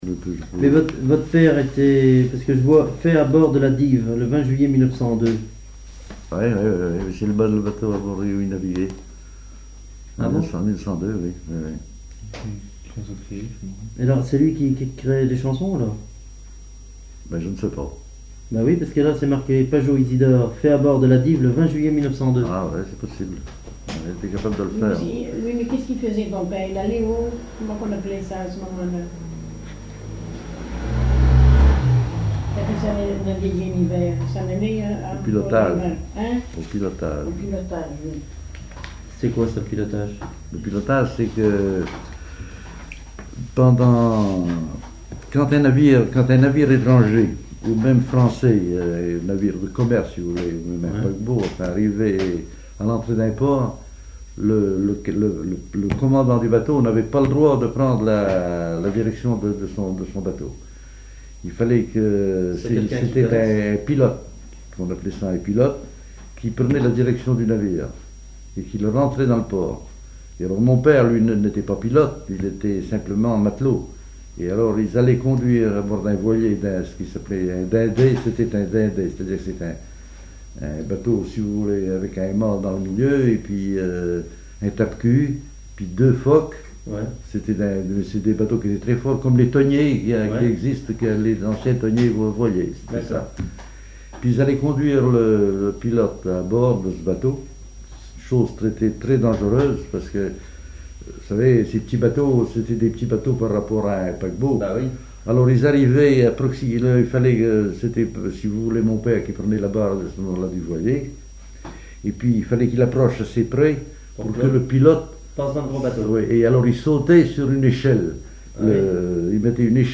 ancien marin pêcheur, charpentier naval
Catégorie Témoignage